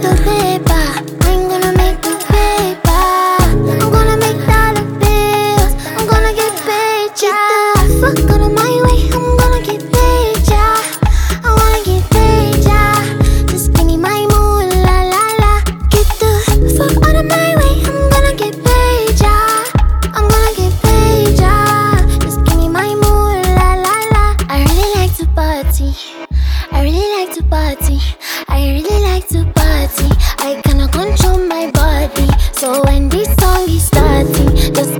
Жанр: Поп / Африканская музыка